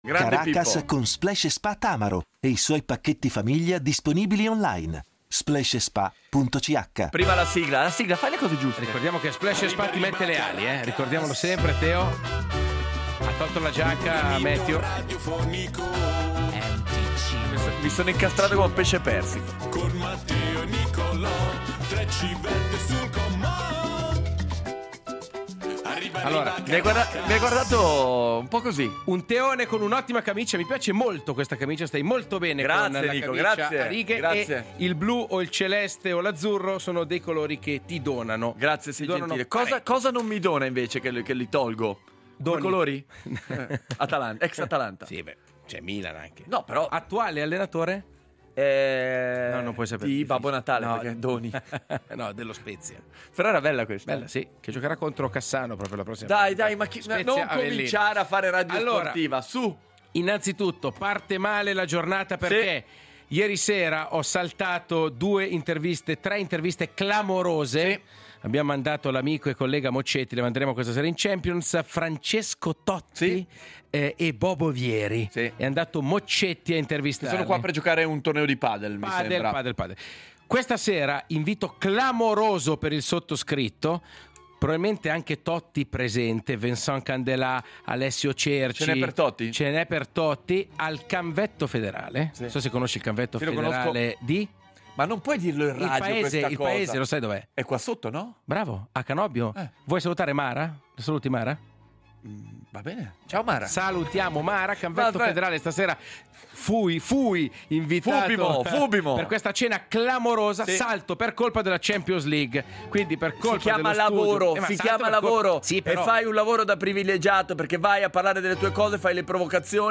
imitazioni di cronisti sportivi